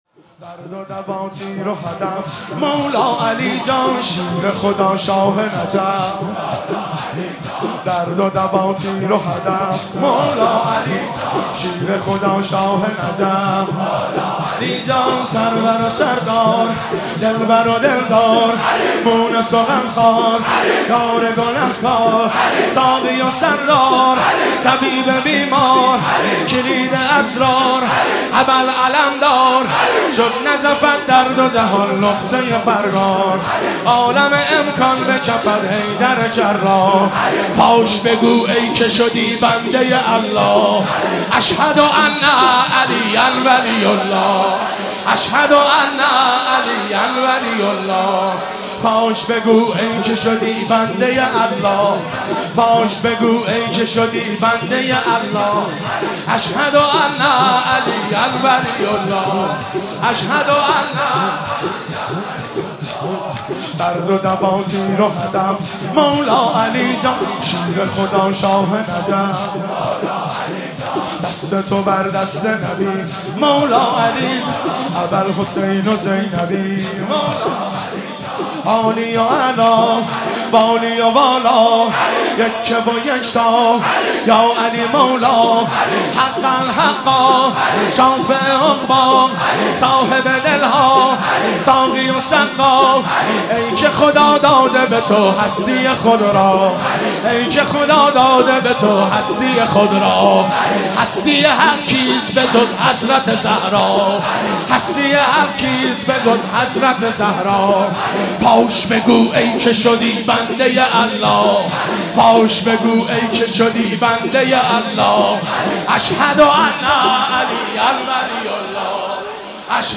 مداحی درد و دا،تیر و هدف(شور)
شام غریبان محرم 1392